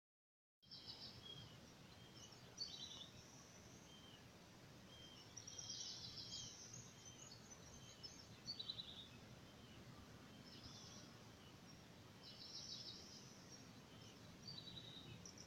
Tschudi´s Nightjar (Quechuavis decussata)
The night jar is heard very faintly on the background, with a repetitive cueoo, cueoo, cueoo, cueoo. Some early house wrens are heard on the foreground.
Detailed location: Lima Cricket
Certainty: Recorded vocal